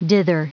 Prononciation du mot dither en anglais (fichier audio)
Prononciation du mot : dither